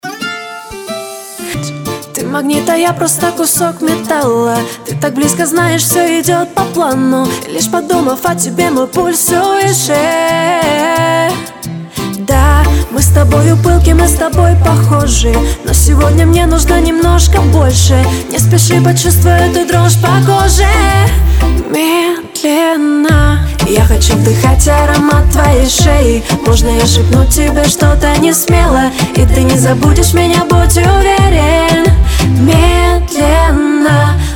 • Качество: 320, Stereo
поп
женский вокал
dance
Cover
Reggaeton